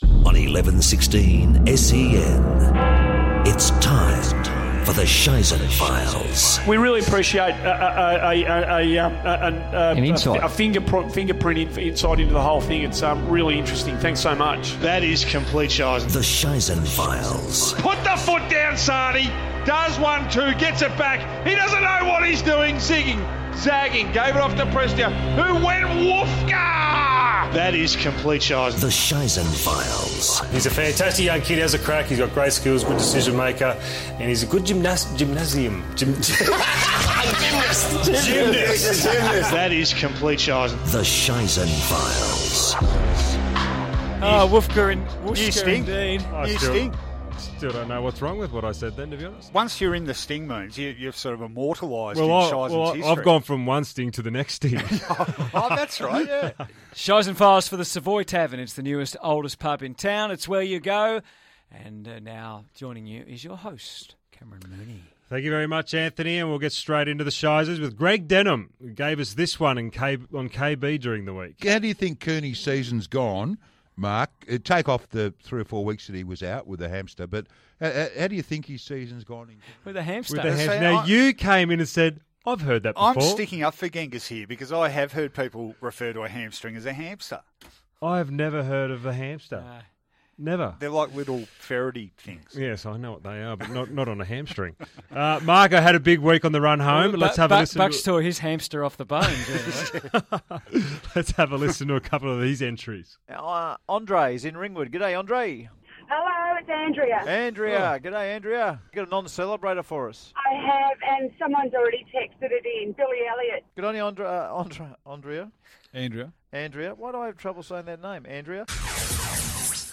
Cameron Mooney presents the week's most humorous on-air stuff-ups.